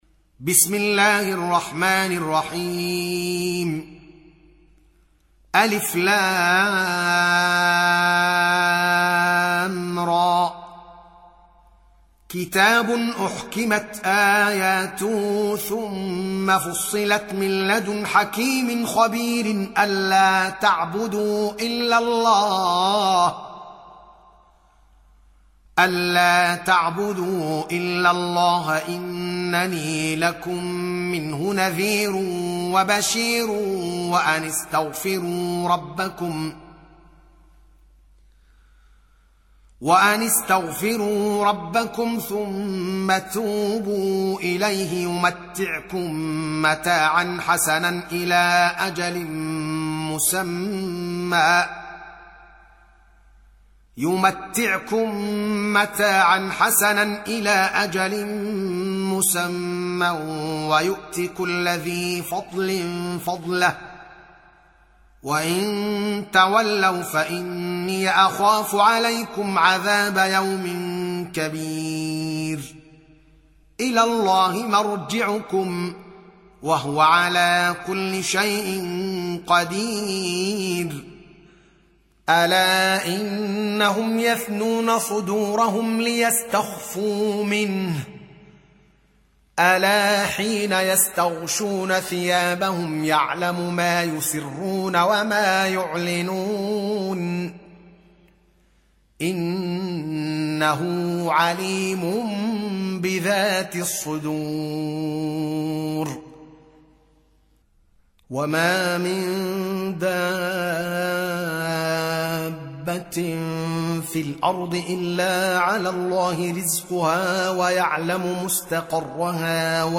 11. Surah H�d سورة هود Audio Quran Tarteel Recitation
Surah Repeating تكرار السورة Download Surah حمّل السورة Reciting Murattalah Audio for 11.